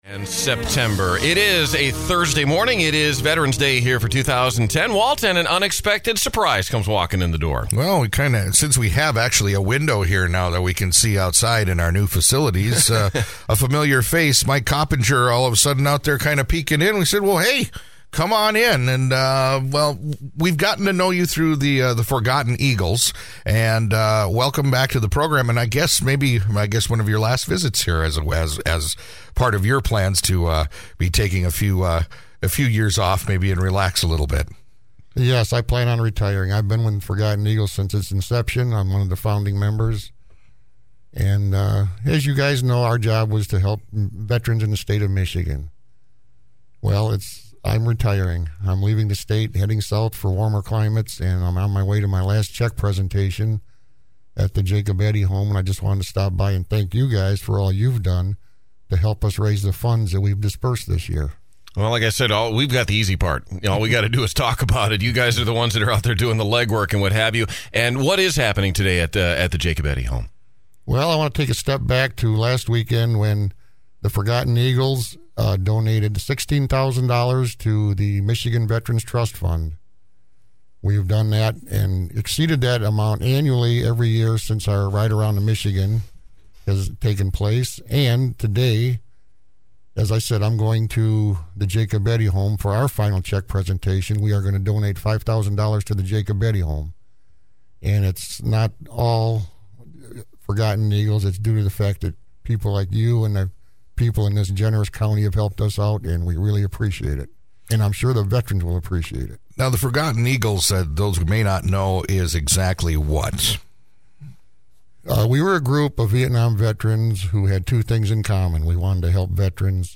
He joined us to discuss who the Forgotten Eagles are, what they do and how they help veterans in need and their families. His visit put a great exclamation point on the day, and to listen to the interview, please click below.